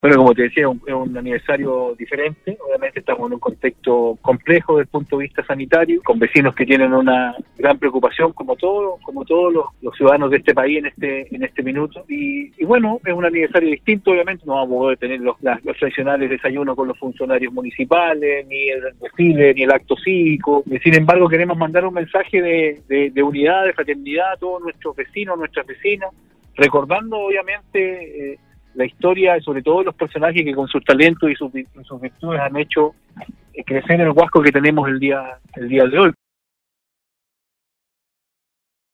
Este jueves la comuna de Huasco cumple 170 años, Nostálgica conversó con su alcalde Rodrigo Loyola quien destacó la trascendencia  de este importante hito llenó de historias y tradiciones, que han permitido el desarrollo de la comuna, que si bien es pequeña no está exenta de dificultades que con el pasar de los años han logrado ir superando.